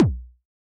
RDM_Raw_SY1-Kick02.wav